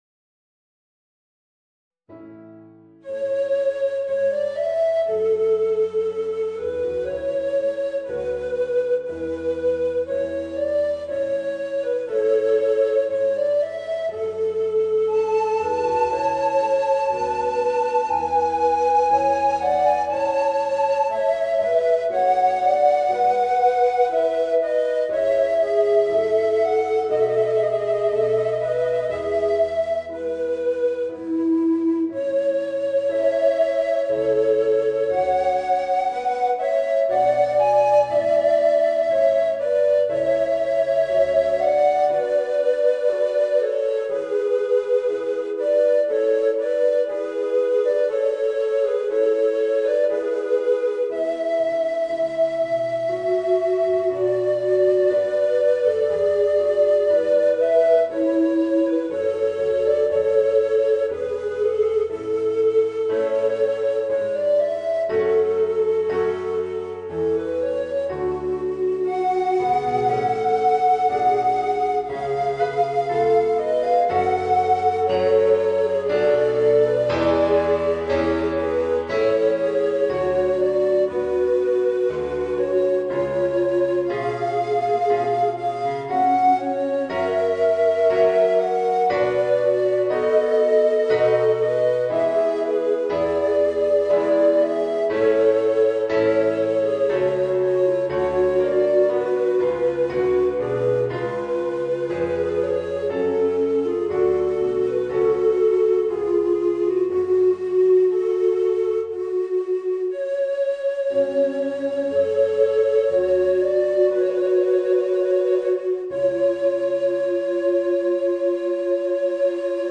Voicing: 2 Tenor Recorders and Piano